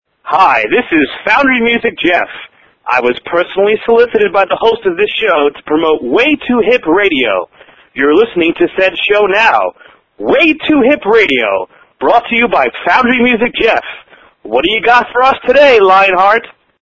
LINER
Category: Radio   Right: Personal